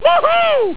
woohoo.au